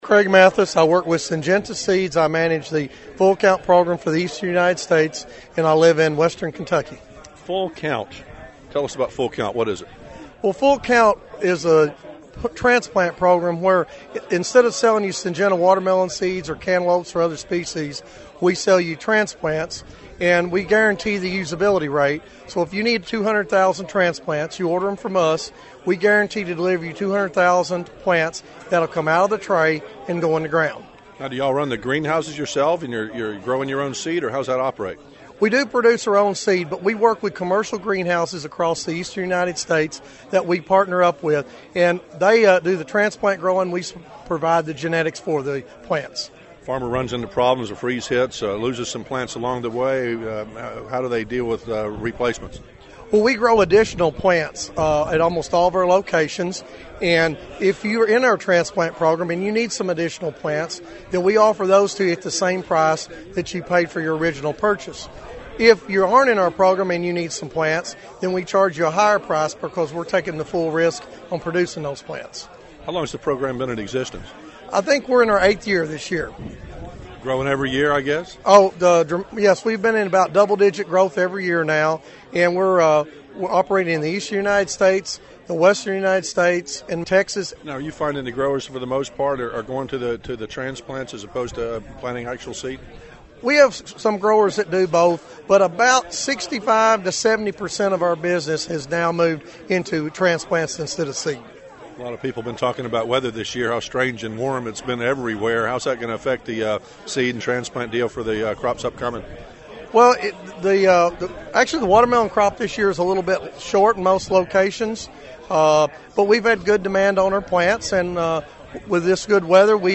Southeastern growers, in this news post you can hear what’s being said in this crowd that will affect what’s grown this year on the farms in this deep southeast region.